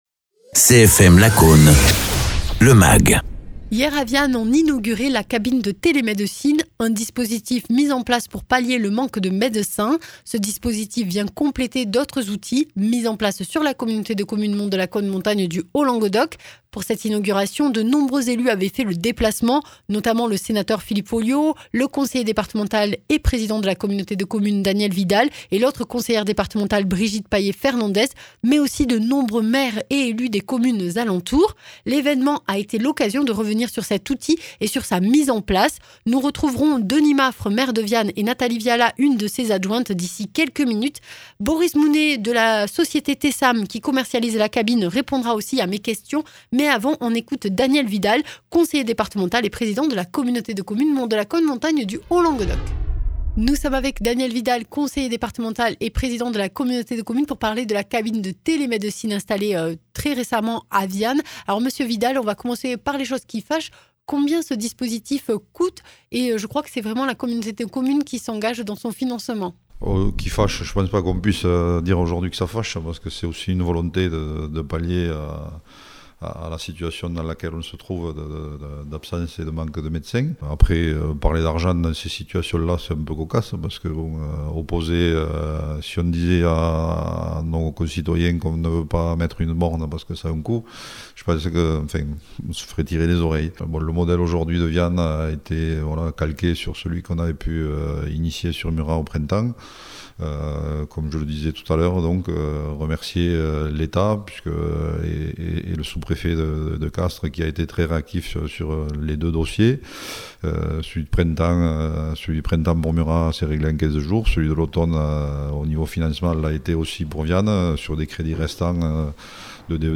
Interviews
Invité(s) : Daniel Vidal, conseiller départemental du Tarn et président de la communauté de communes Monts de Lacaune, Montagne du Haut Languedoc